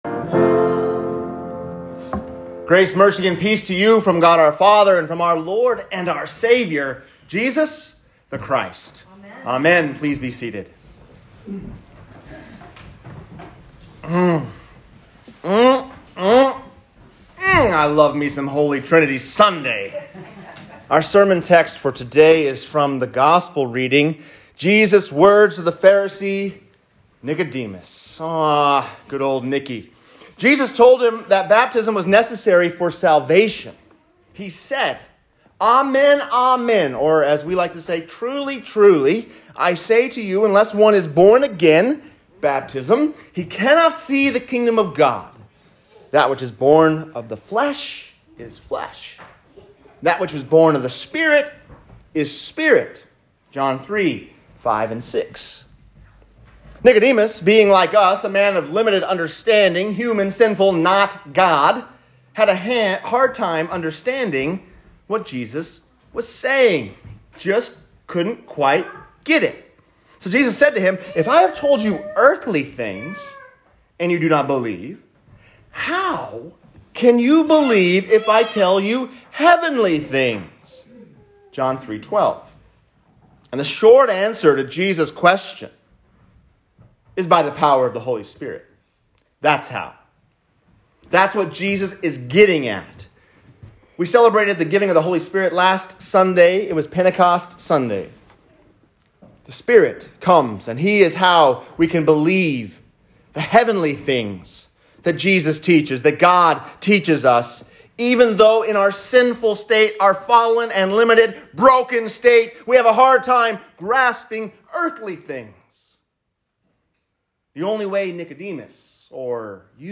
The Higher Sound | Sermon – St. Mark Lutheran Church
The Higher Sound | Sermon